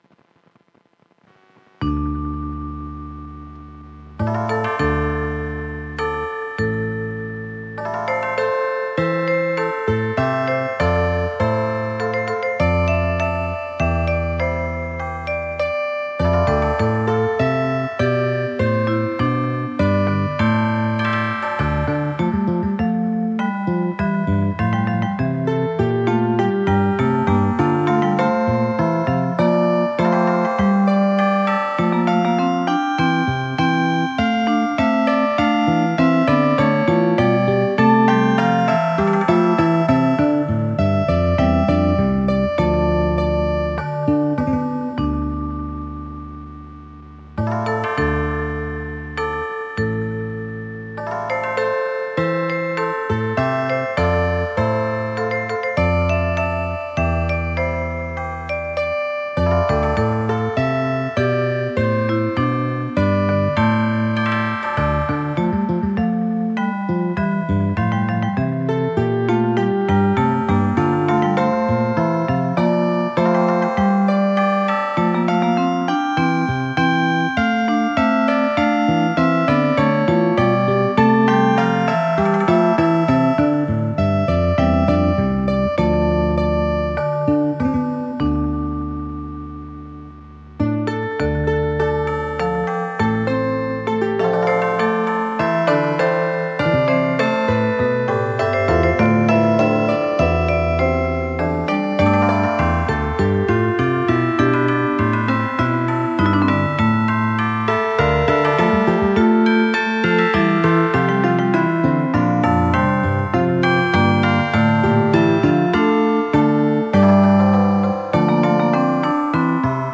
CHANSON ; POLYPHONIC MUSIC